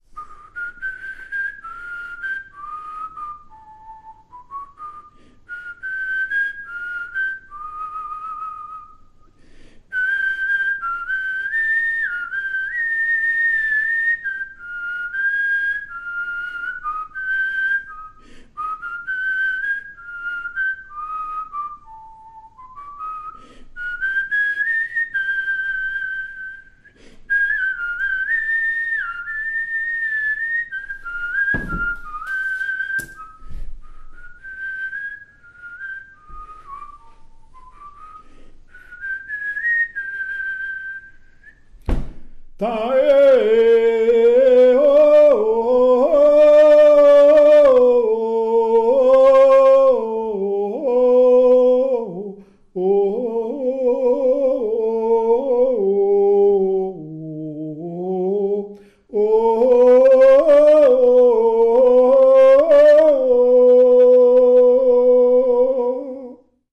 Sifflement et dariolage
Appels de labour, tiaulements, dariolage, teurlodage, pibolage
couplets vocalisés
sifflement et chant pour mener les boeufs constitué d'onomatopées, interjections et de noms de boeufs
Pièce musicale inédite